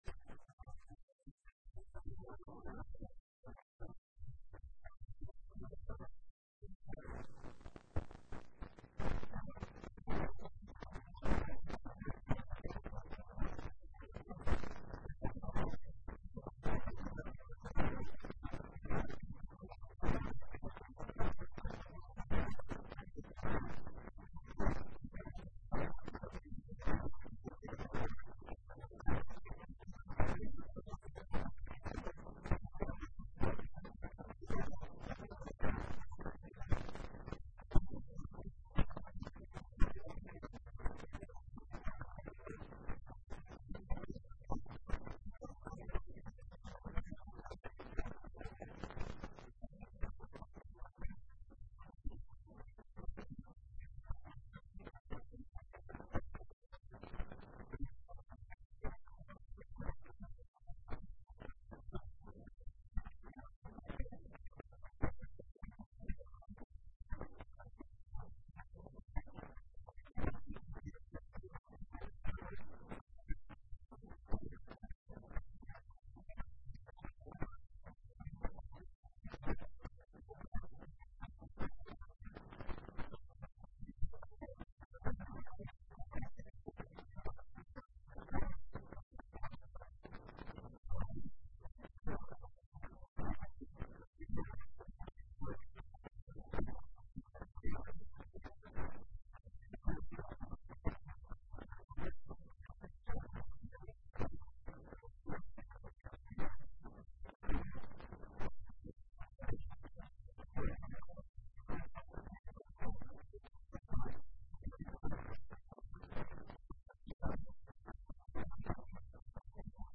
Assamese-Morning-News-0755.mp3